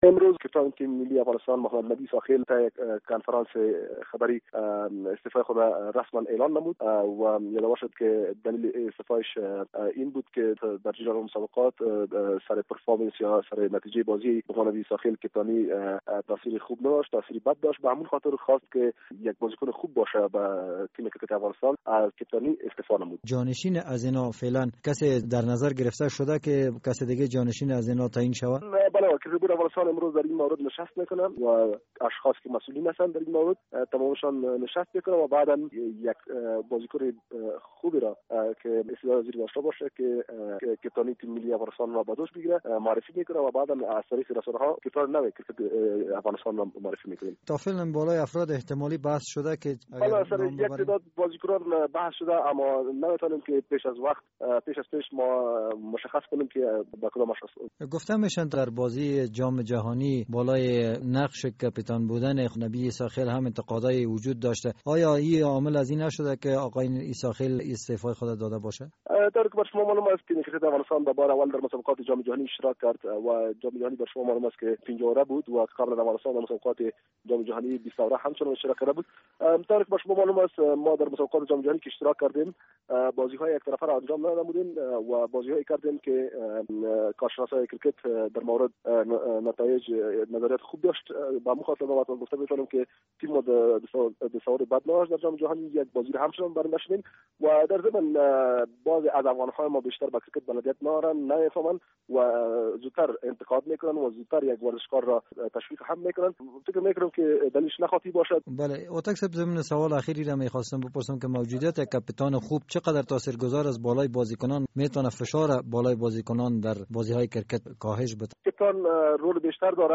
مصاحبه‌های ورزشی